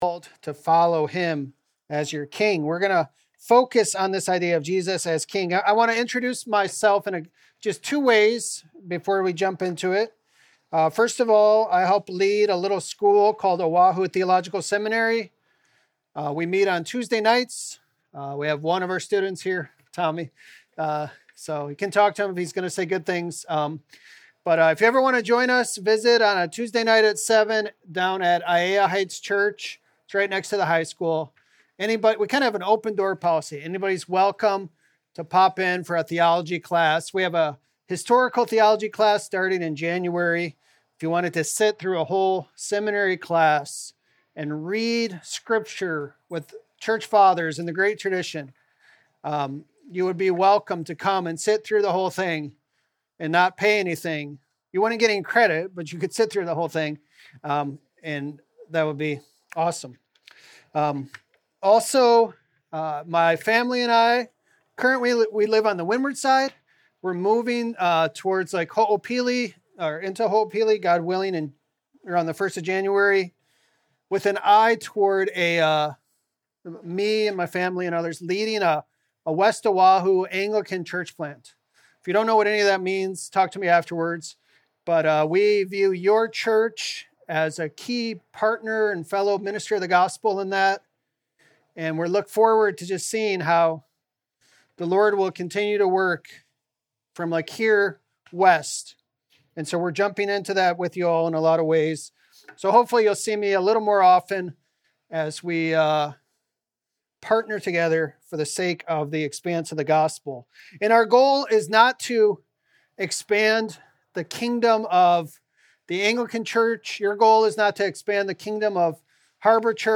2025 Christ the King Preacher